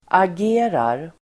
Uttal: [ag'e:rar]